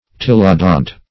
Tillodont \Til"lo*dont\